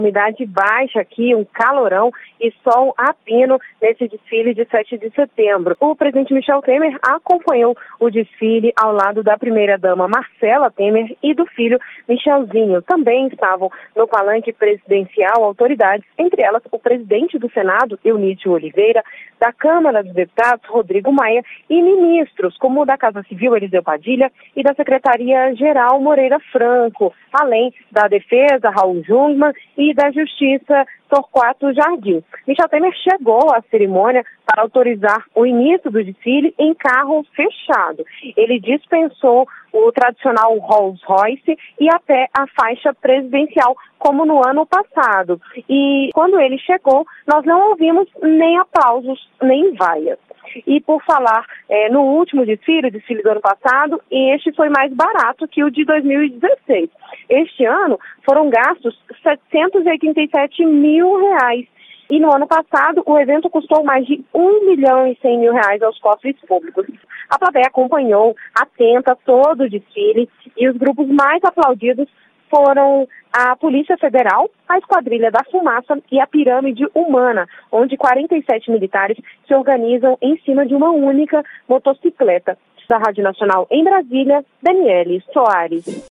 Público aplaude Esquadrilha da Fumaça, pirâmide humana e PF no desfile da Independência em Brasília